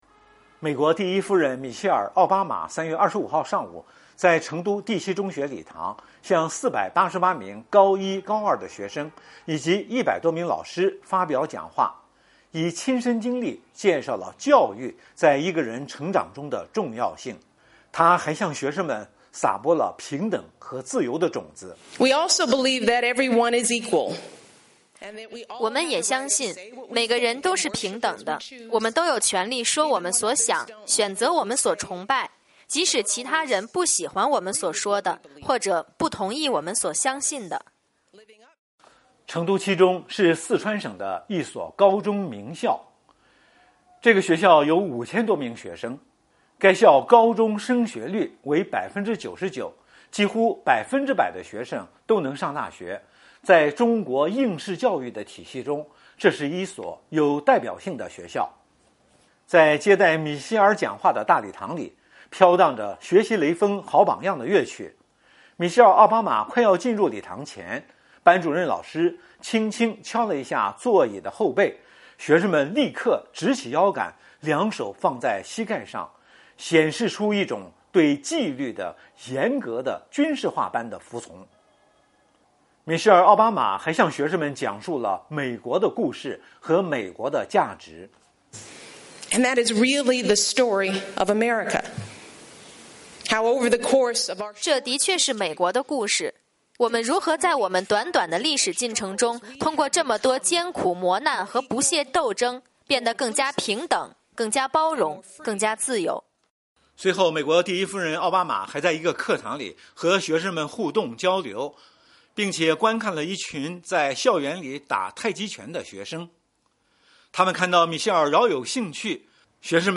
成都 —  米歇尔·奥巴马在访华第五天，参观了成都第七中学，并发表了演讲。奥巴马夫人还和学生们进行了互动，并回答了学生们的问题。